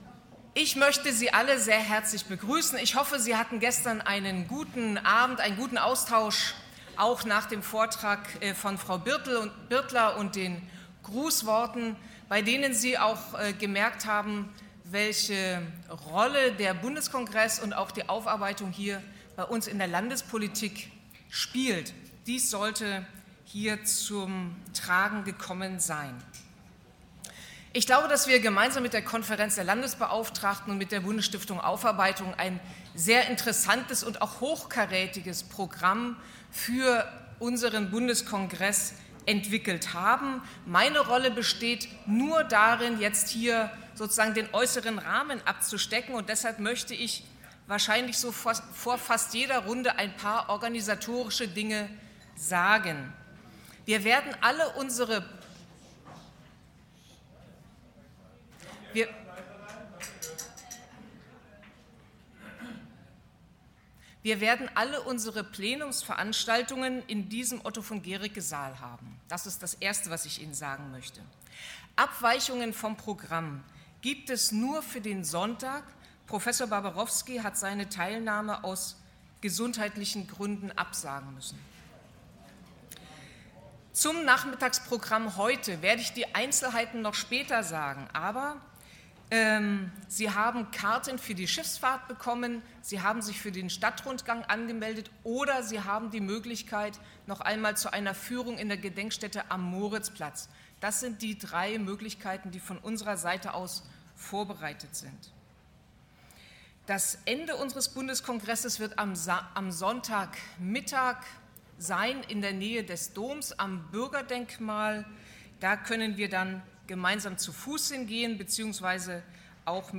Audiodokumentation Bundeskongress 2017: „Erinnern und Zeichen setzen! – Zeugnisse politischer Verfolgung und ihre Botschaft.“ (Teil 2: 29. April vormittags)
Begrüßung Birgit Neumann-Becker